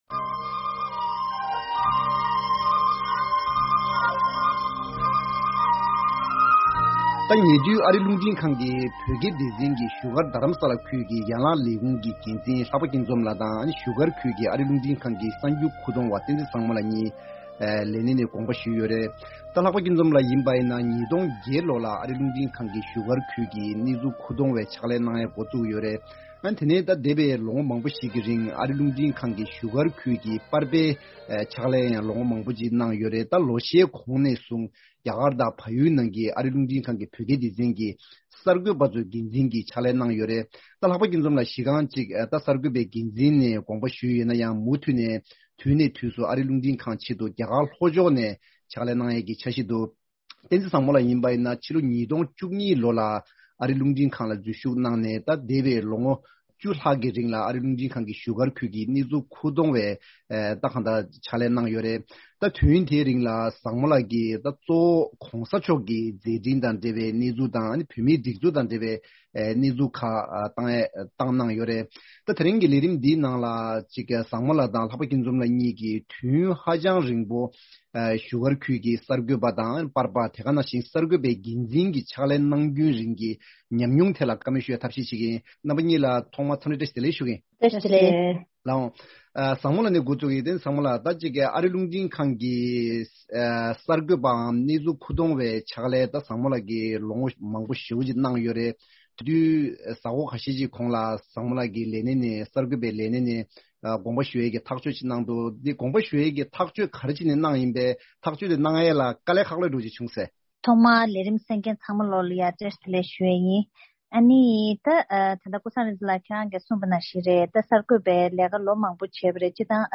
Table Talk: Reflections and Experiences of Two Former VOA Reporters.